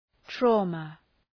Προφορά
{‘traʋmə}